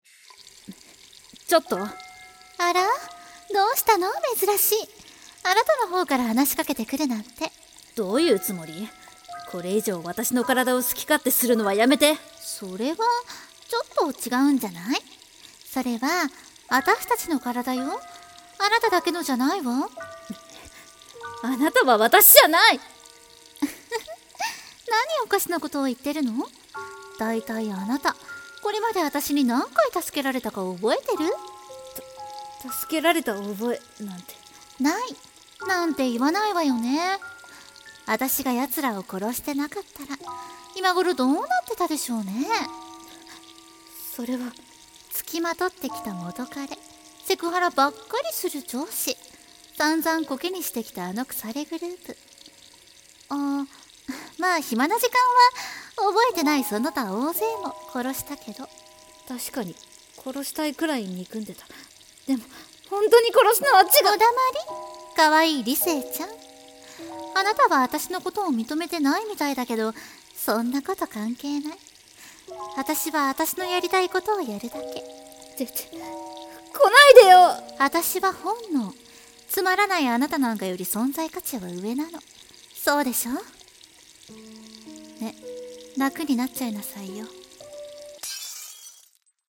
声劇 理性と本能(女性ver.)